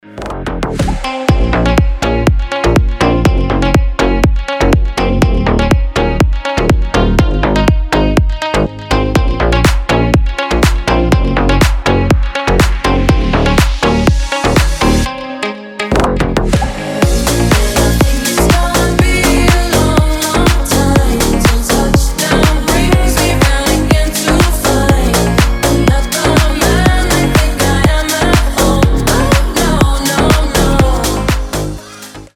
• Качество: 320, Stereo
deep house
Dance Pop
ремиксы